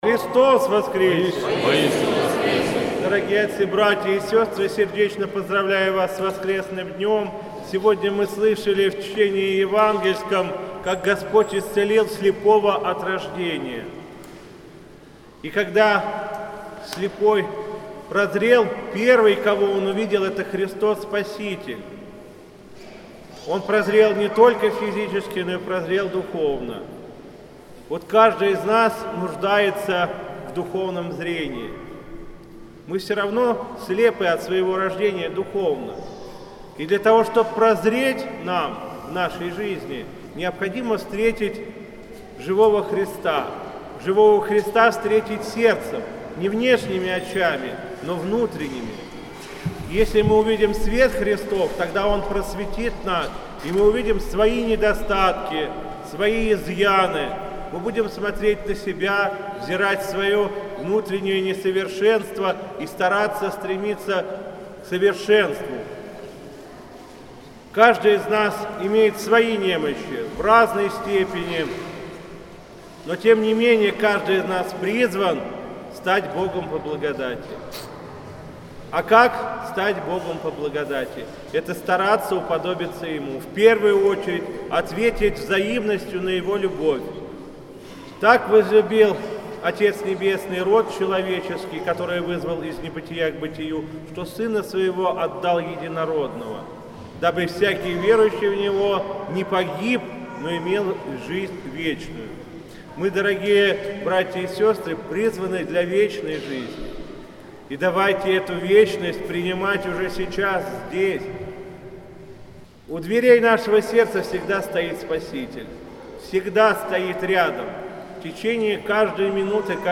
По окончании богослужения правящий архиерей обратился к присутствующим с архипастырским словом.